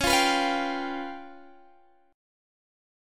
Db7b5 Chord
Listen to Db7b5 strummed